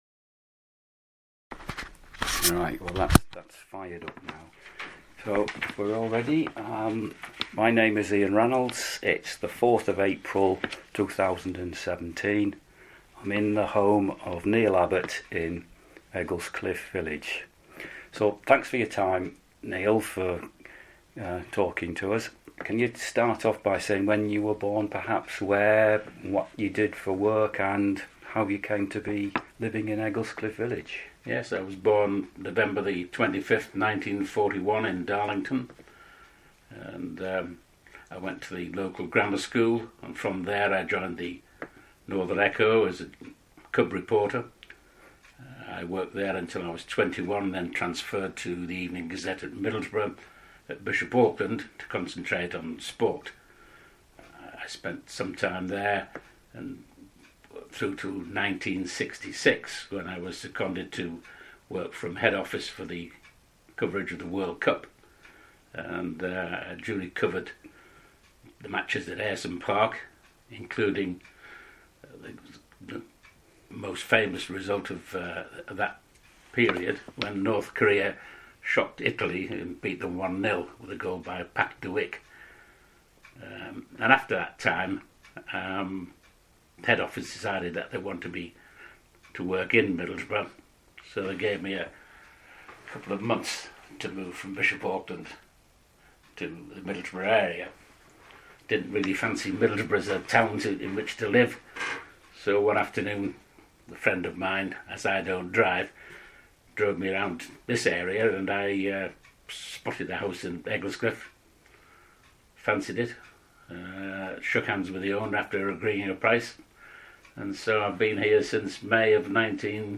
You can follow what he says in his oral history by clicking the above and sitting back and enjoying what he says.